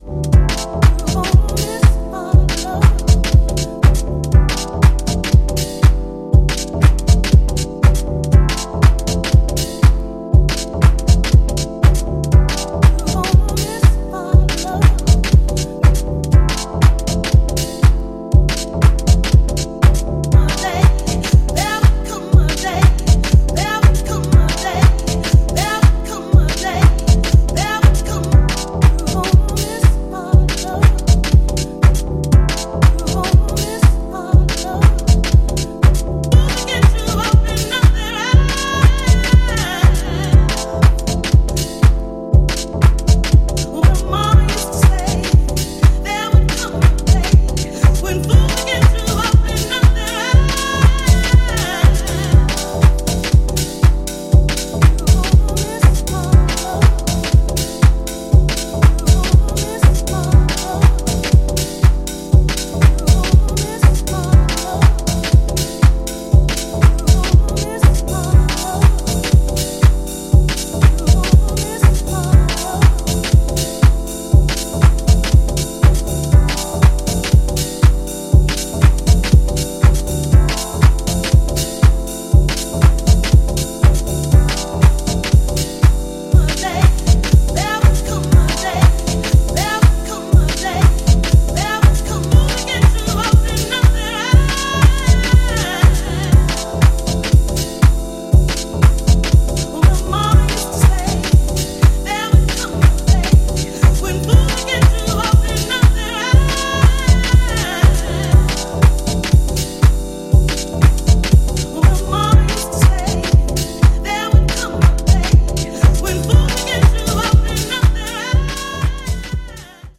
ラフな質感とメランコリーがフロアに浸透していく、非常にエッセンシャルな内容です！